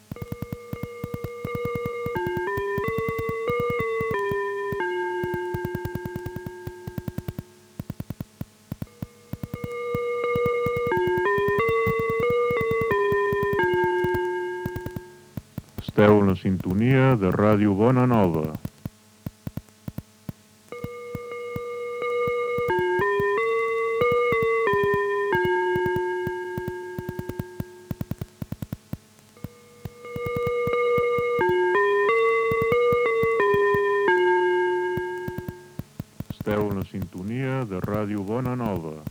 Sintonia i identificació